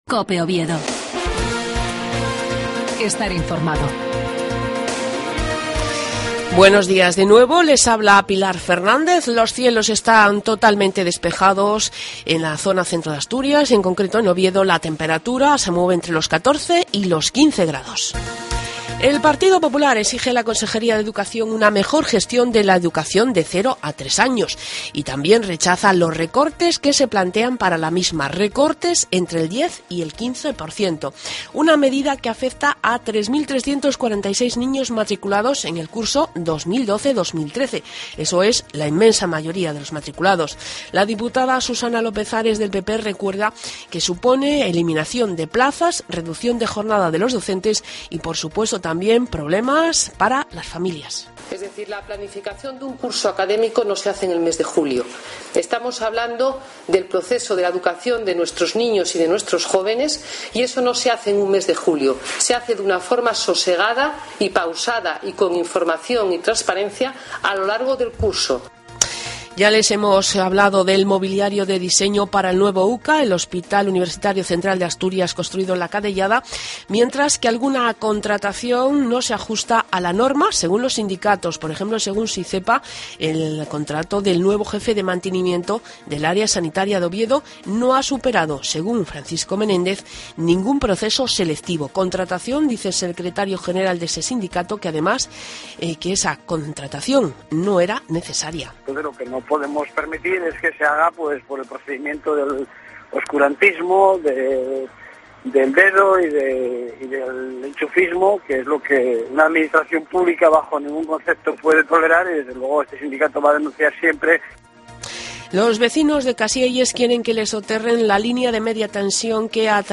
AUDIO: LAS NOTICIAS DE OVIEDO A PRIMERA HORA DE LA MAÑANA.